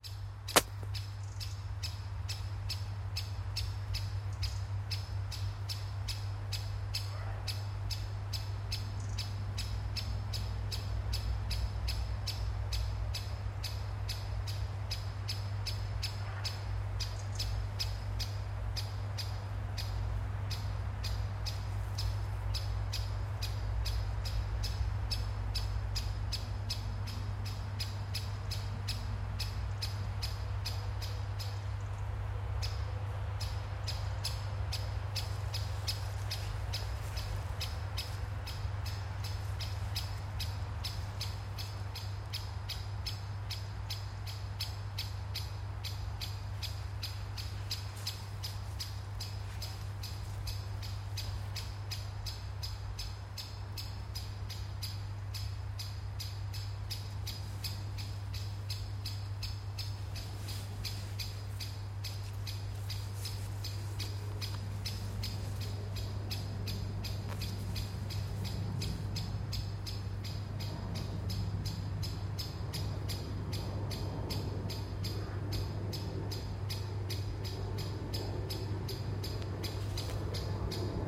Great Spotted Woodpecker, Dendrocopos major
Ziņotāja saglabāts vietas nosaukumsKurzemes prospekts - parks starp mājām
NotesFonā var dzirdēt arī Paceplīša saucienus